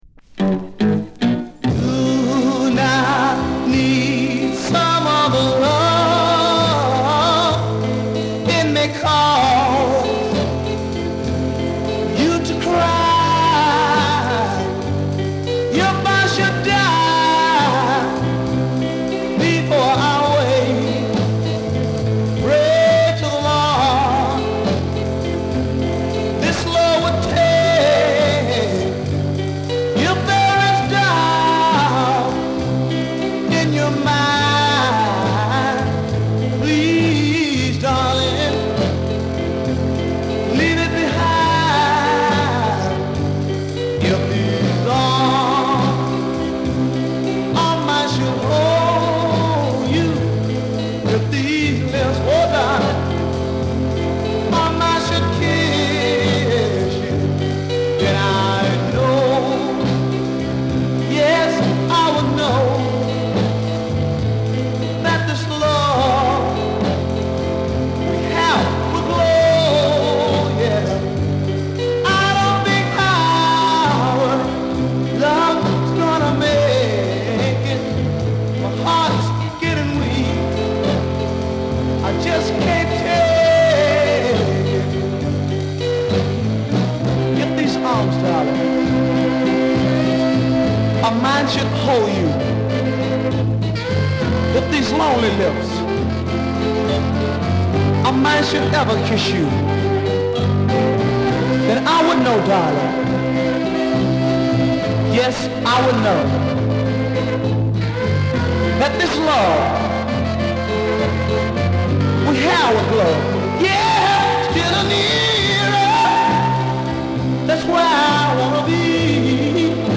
• R&B / BLUES / DOO WOP / BLACK ROCKER